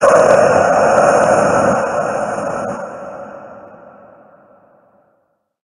Cri de Dracaufeu Gigamax dans Pokémon HOME.
Cri_0006_Gigamax_HOME.ogg